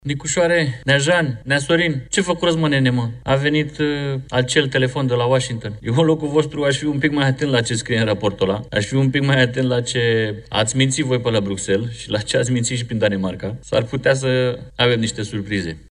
Ironii în Parlamentul României după raportul american care susține că ar fi existat un amestec al Comisiei Europene în mai multe țări europene. AUR a profitat de apariția acestui document pentru a ironiza actuala guvernare.
Deputatul AUR, Florin Bogdan Velcescu: „Eu, în locul vostru, aș fi puțin mai atent la ce scrie în raportul ăla”